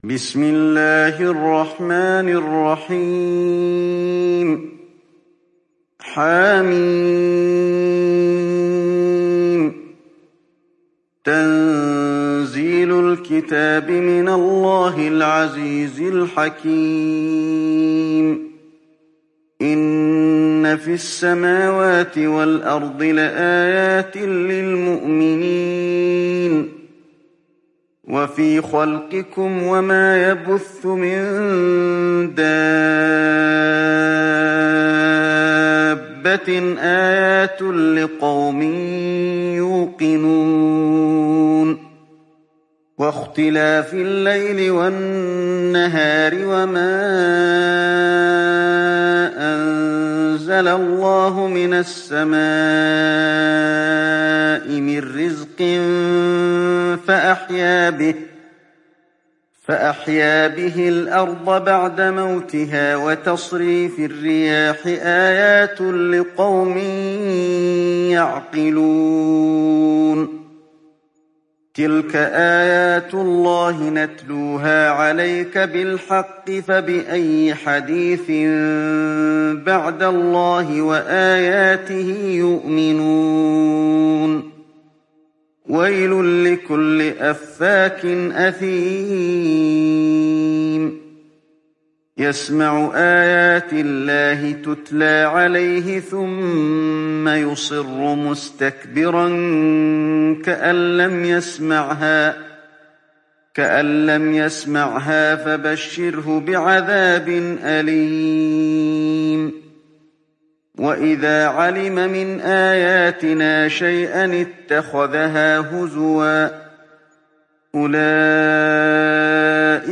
تحميل سورة الجاثية mp3 بصوت علي الحذيفي برواية حفص عن عاصم, تحميل استماع القرآن الكريم على الجوال mp3 كاملا بروابط مباشرة وسريعة